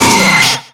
Cri de Scarabrute dans Pokémon X et Y.